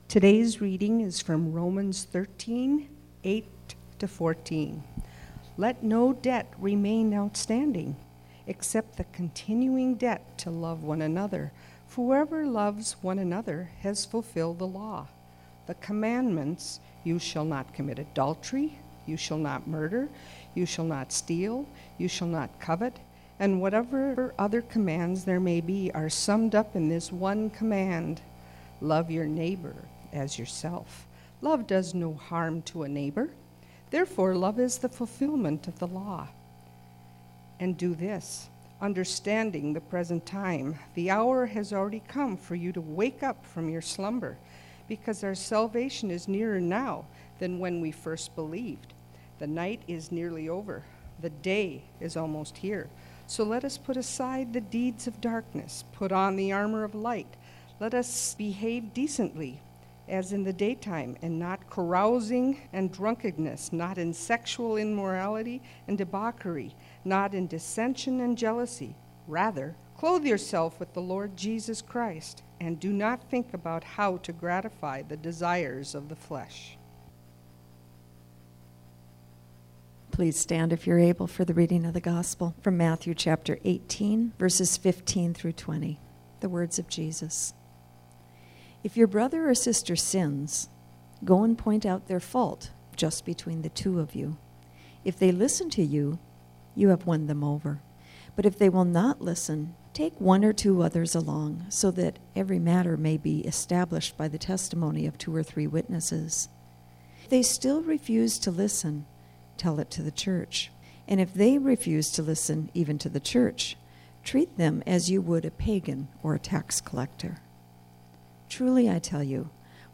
2020-September-6-sermon.mp3